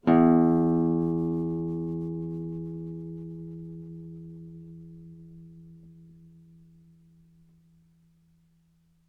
DET25-AdaptiveGuitar/Assets/AudioSources/Guitar Chords/Note_E.wav at main
Note_E.wav